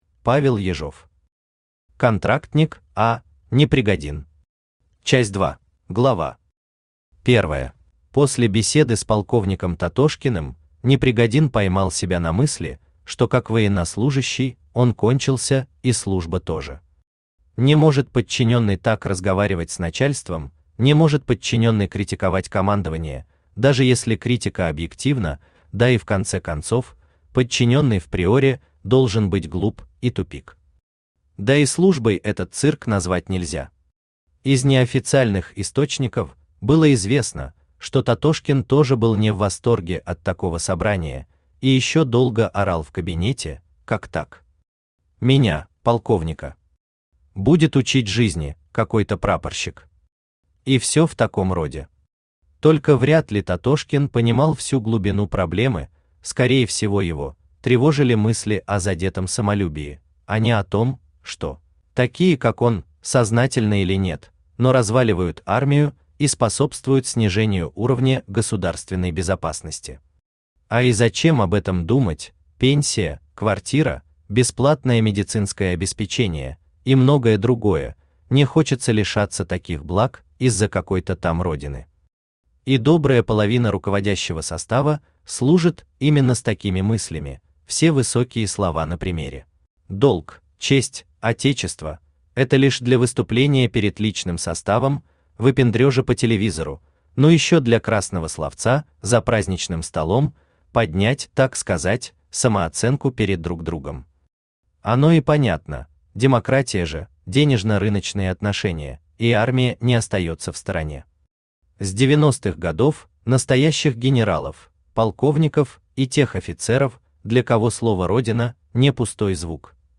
Аудиокнига Контрактник А. Непригодин. Часть 2 | Библиотека аудиокниг
Часть 2 Автор Павел Александрович Ежов Читает аудиокнигу Авточтец ЛитРес.